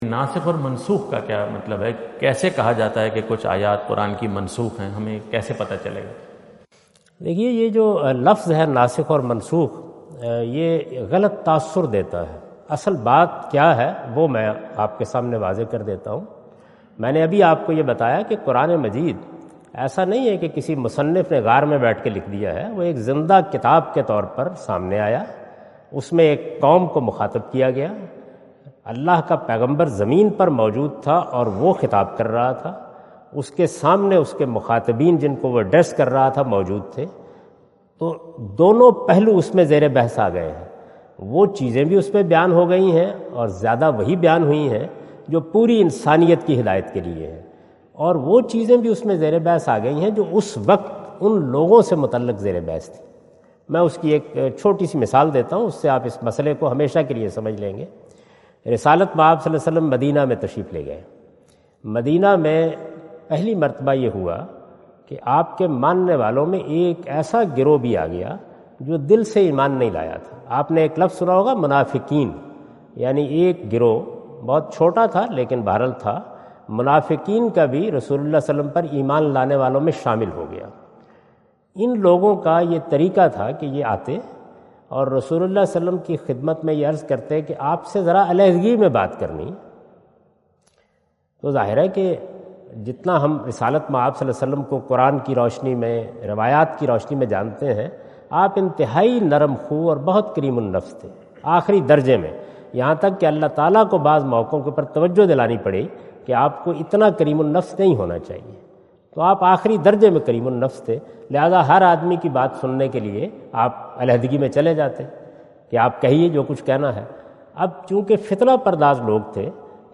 Javed Ahmad Ghamidi answer the question about "How can we determine which ayah is ‘Nasikh’ or ‘Mansookh’?" During his US visit at Wentz Concert Hall, Chicago on September 23,2017.